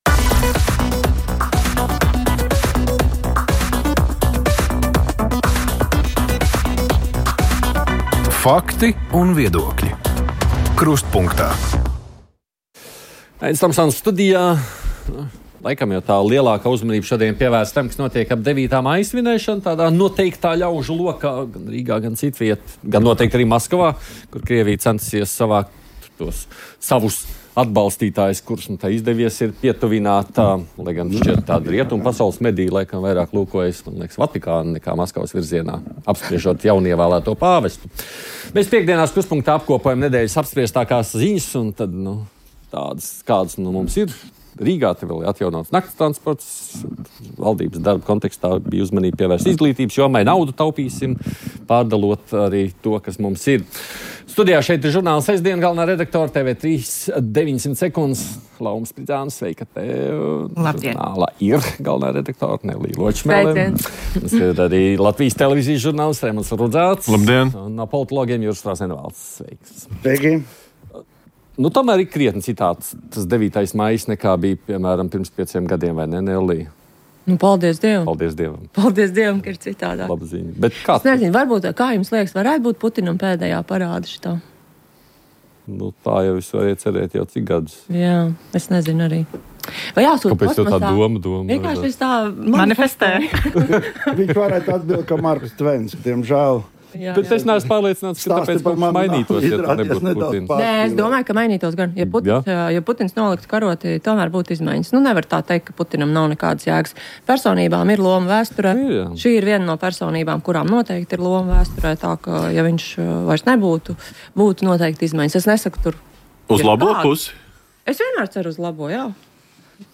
Šodien mūsu uzmanības lokā veselības aprūpes pakalpojumi Latvijā, primāri jau ģimenes ārstu pieejamība, kas ir pamatu pamats, lai cilvēki varētu dzīvot tur, kur viņi dzīvo. Iepriekš izskanēja kolēģu gatavota reportāža, tagad laiks sarunai studijā.